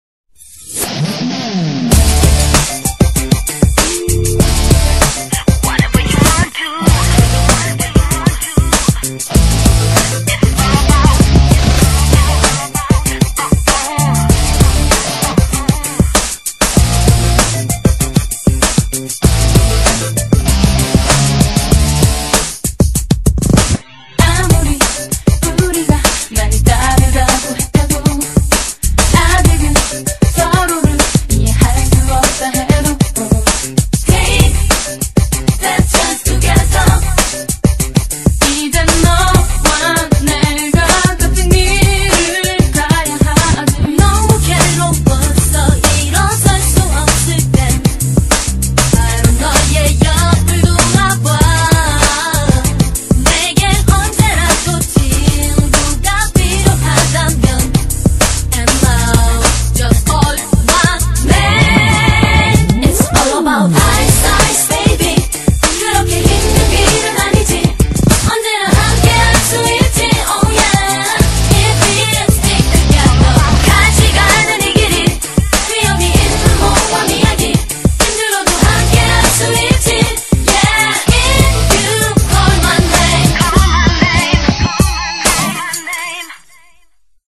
BPM97--1
Audio QualityPerfect (High Quality)